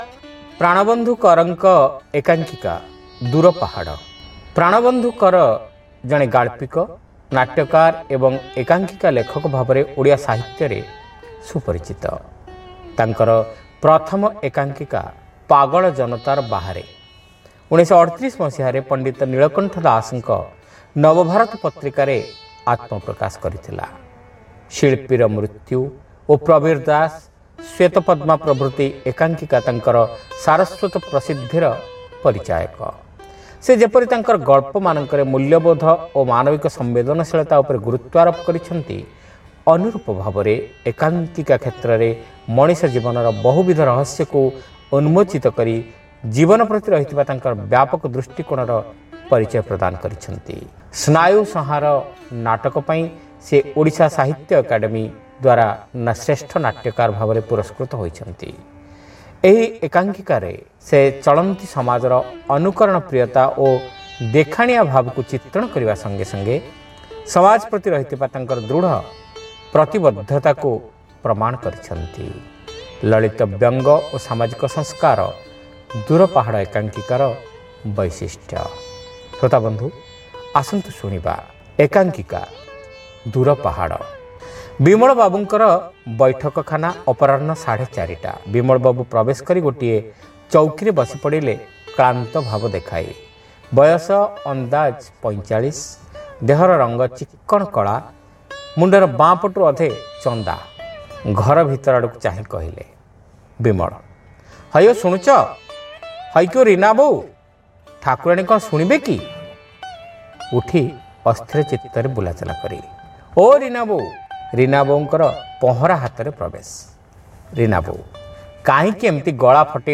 Audio One act Play : Dura Pahada (Part-1)